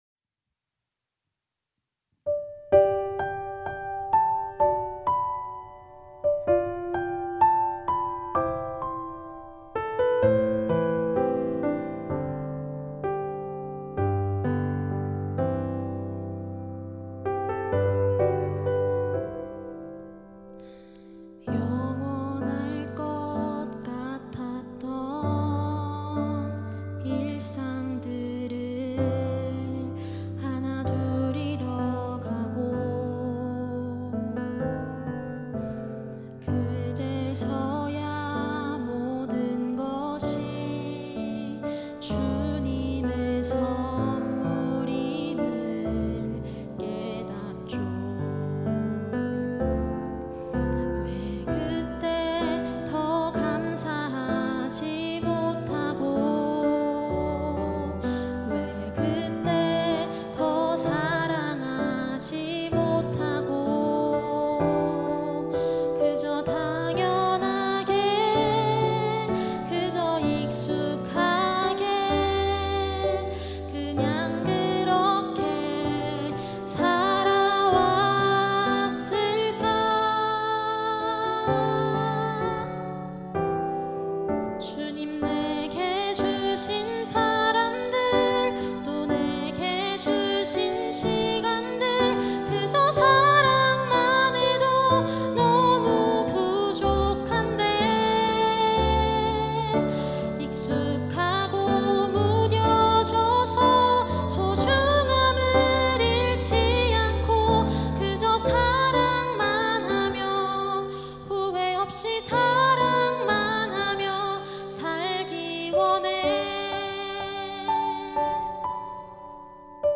특송과 특주 - 그저 사랑만 하며